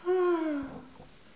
ah-delassement_01.wav